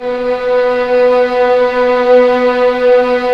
Index of /90_sSampleCDs/Roland LCDP13 String Sections/STR_Violins II/STR_Vls6 p%mf St